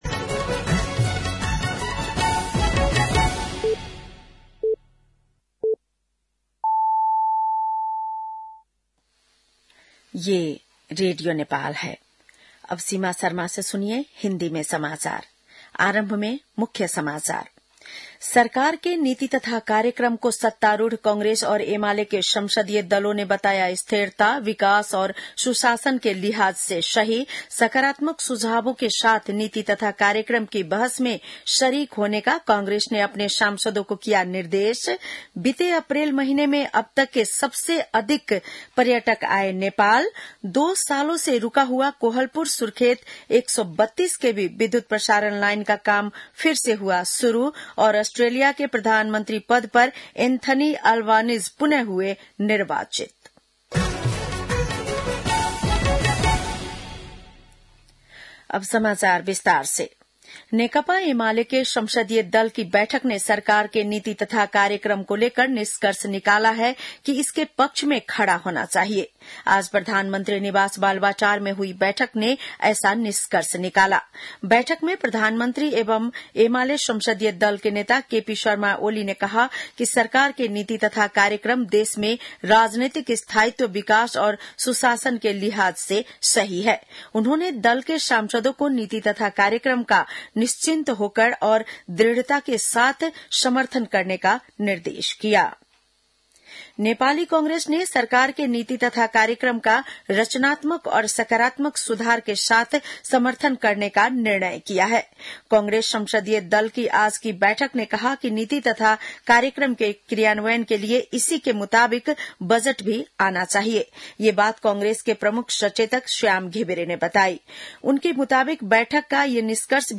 बेलुकी १० बजेको हिन्दी समाचार : २० वैशाख , २०८२
10-pm-NEWS-1-20.mp3